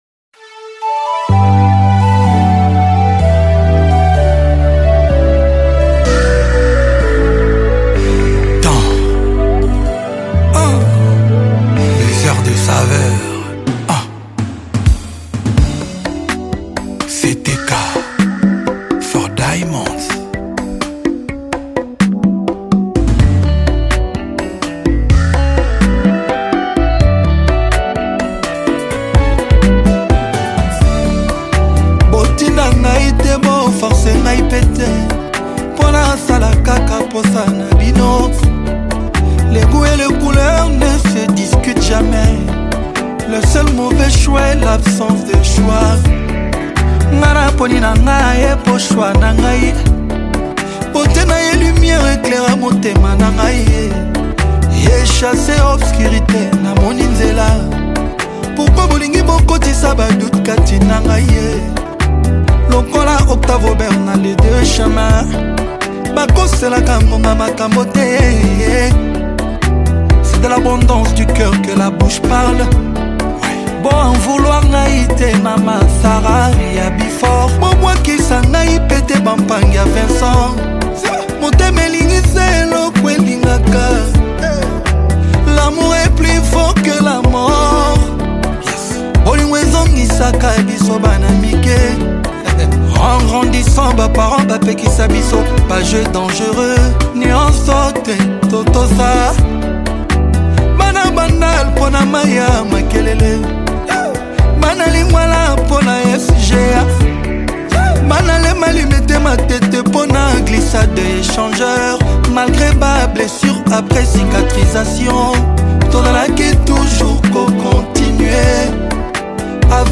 With its soulful delivery and rich instrumentation
Afrobeats